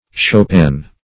chopin - definition of chopin - synonyms, pronunciation, spelling from Free Dictionary
Chopin \Chop"in\, n. [F. chopine, fr. G. schoppen.]